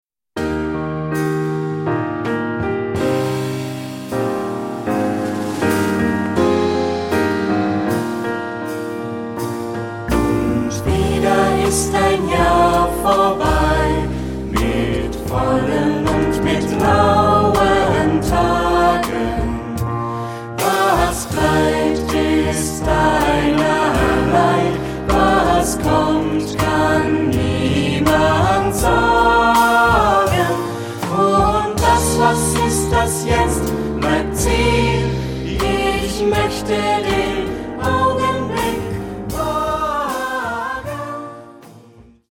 Kanon.
Choraljazz
harmonisch ; festlich ; langsam
Ad libitum (3 Ad libitum Stimmen )
Tonart(en): Es-Dur